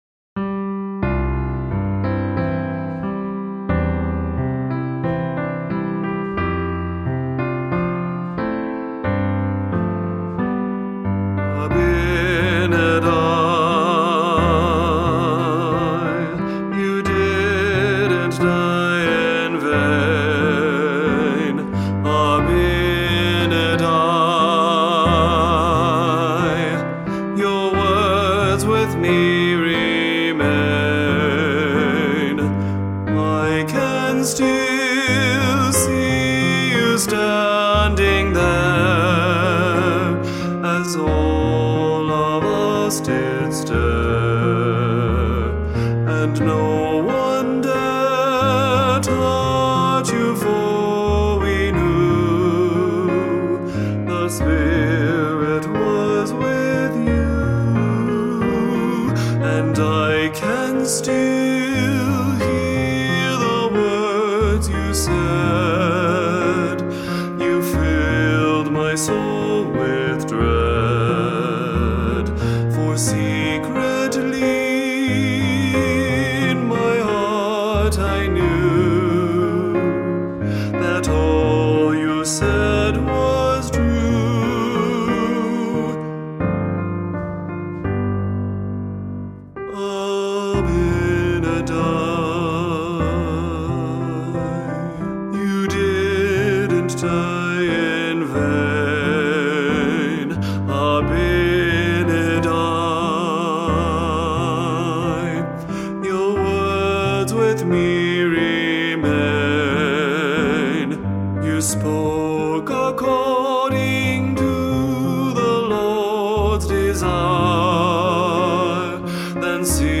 Vocal Solo for Medium Voice with Piano